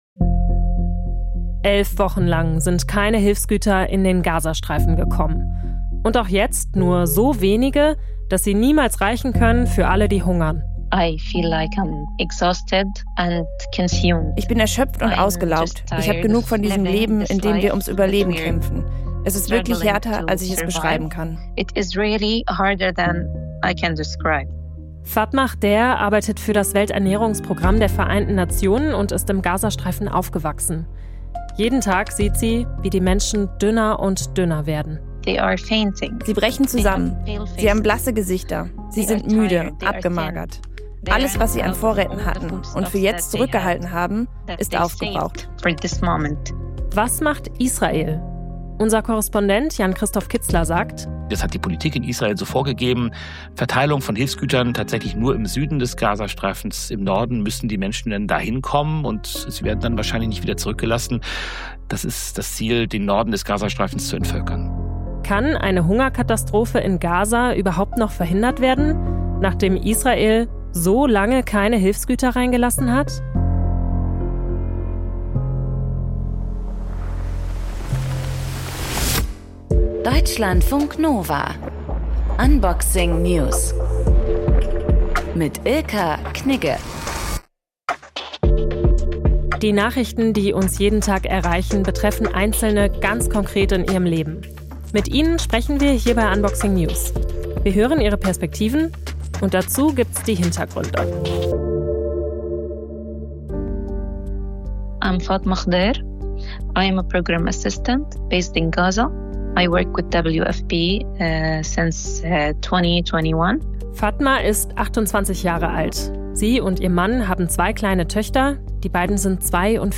Aus dem Podcast Interview Podcast abonnieren Podcast hören Podcast Interview Das Interview im Deutschlandfunk Kultur greift kulturelle und politische Trends ebenso auf wie...